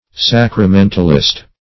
Search Result for " sacramentalist" : The Collaborative International Dictionary of English v.0.48: Sacramentalist \Sac`ra*men"tal*ist\, n. One who holds the doctrine of the real objective presence of Christ's body and blood in the holy eucharist.